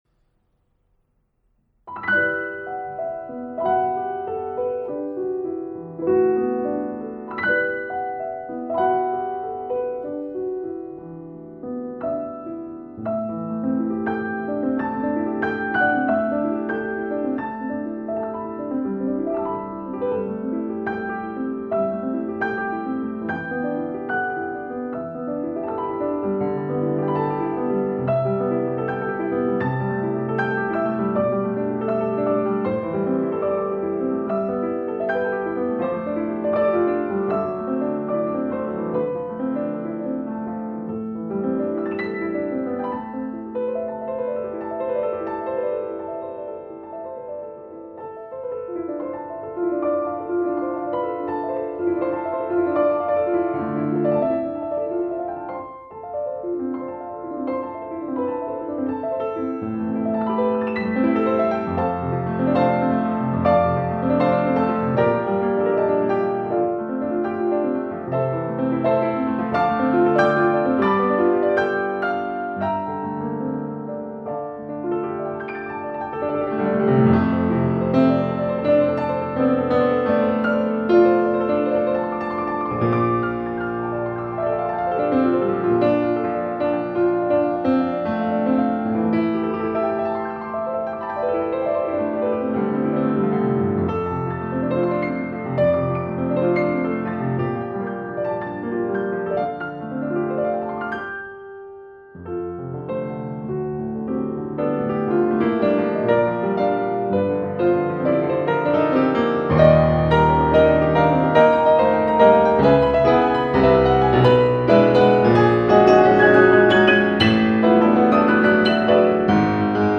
Pianiste